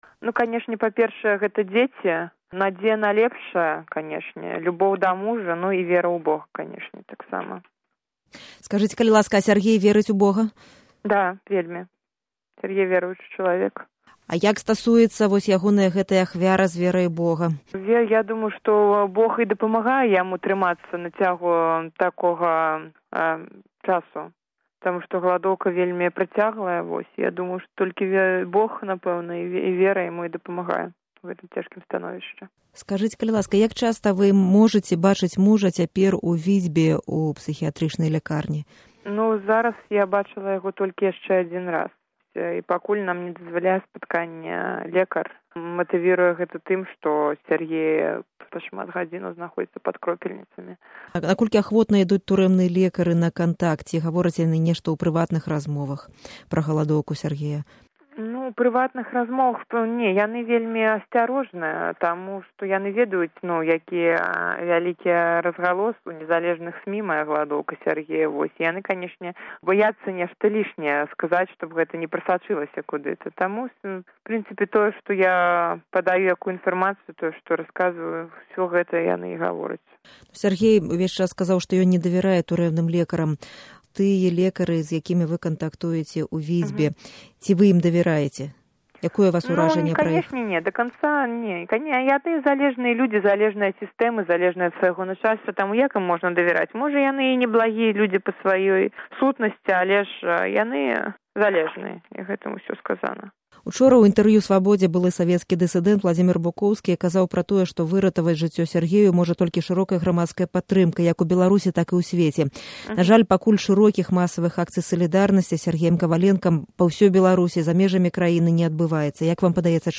Інтэрвію